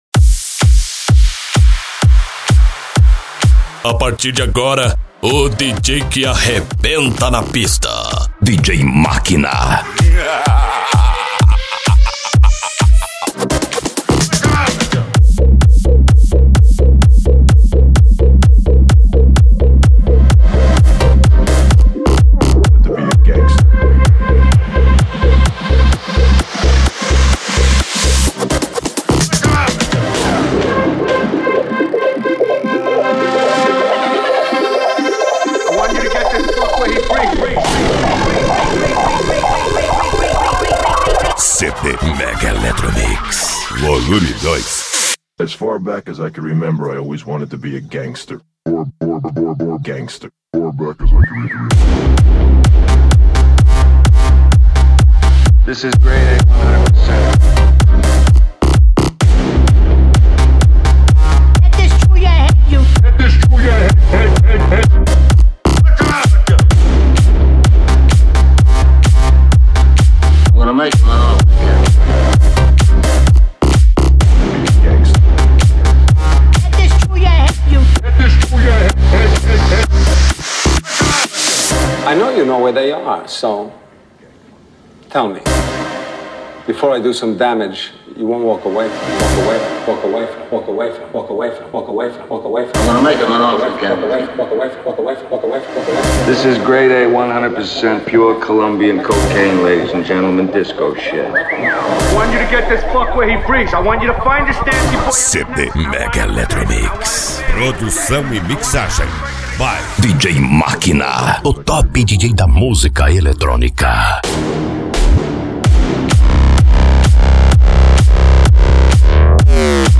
Electro House
Minimal
Psy Trance
Remix